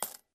coin_coin_5.ogg